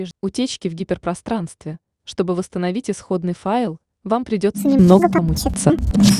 голосовой помощник